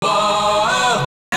Key-chant_67.1.1.wav